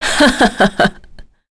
Tanya-Vox-Laugh.wav